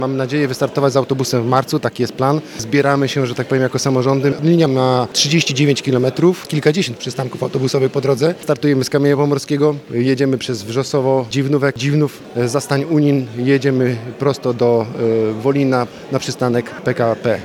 Mówi Łukasz Dzioch, burmistrz Dziwnowa